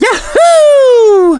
One of three voice clips from Mario in Super Mario Galaxy.
SMG_Mario_Yahoo_(flung).wav